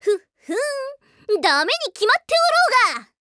Voice